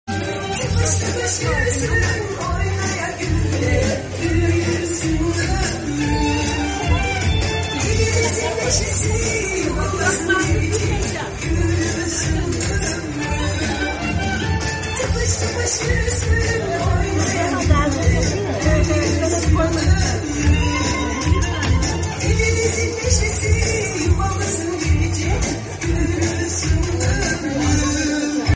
Bebek Kına Türküsü